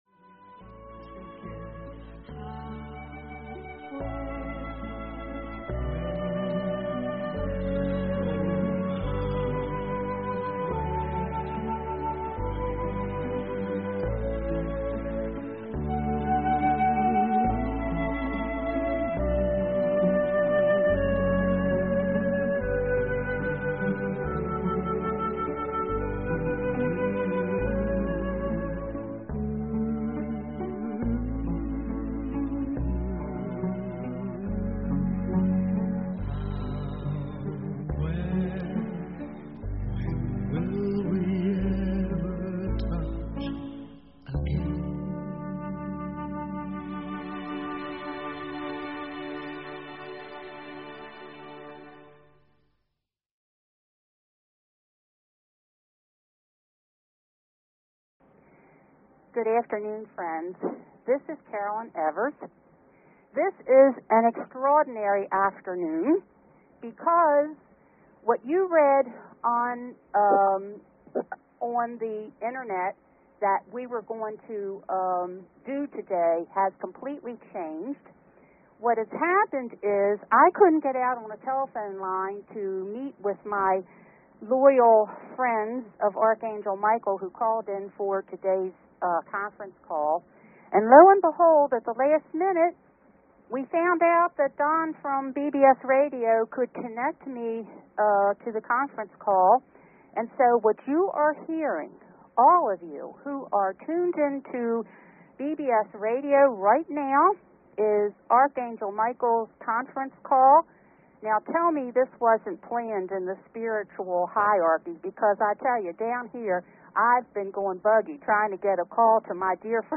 Talk Show Episode, Audio Podcast, The_Message and Courtesy of BBS Radio on , show guests , about , categorized as
LIVE! Archangel Mikael's conference call.